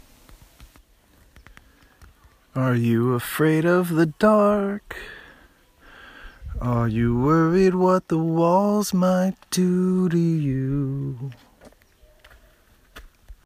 Field Recordings